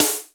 TRASH SNARE.wav